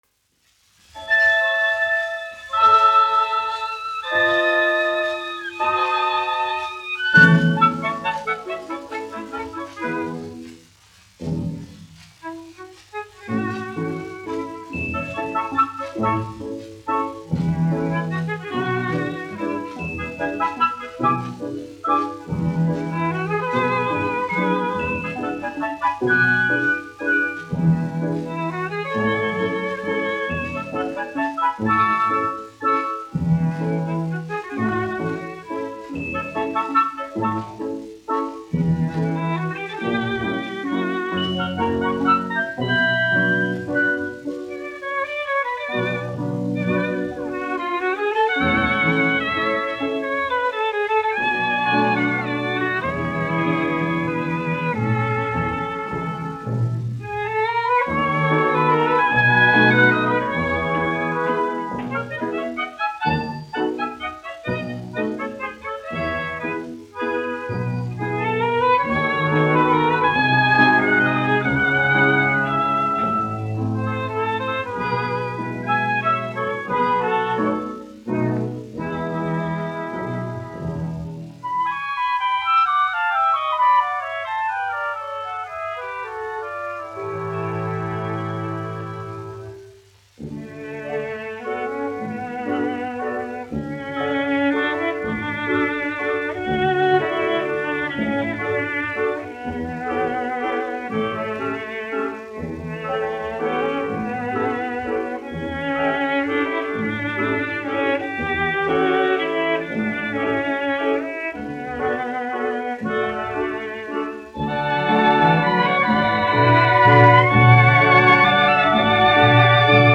1 skpl. : analogs, 78 apgr/min, mono ; 25 cm
Valši
Skaņuplate
Latvijas vēsturiskie šellaka skaņuplašu ieraksti (Kolekcija)